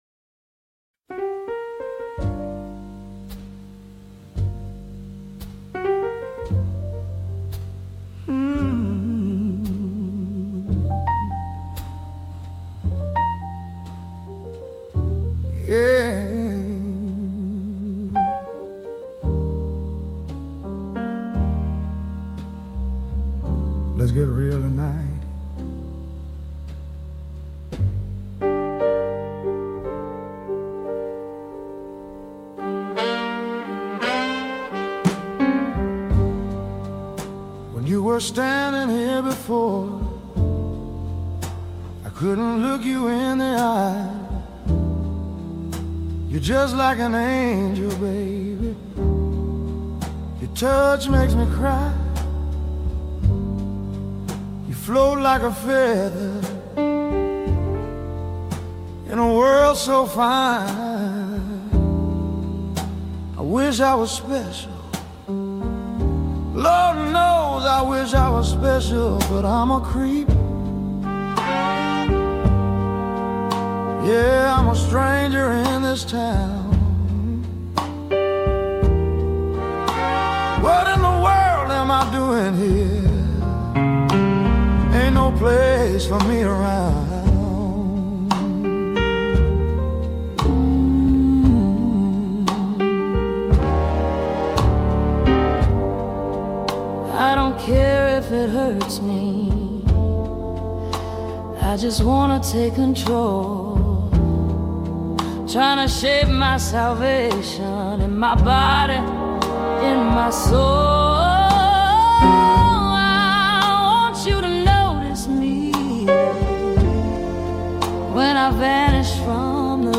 Almost 1950s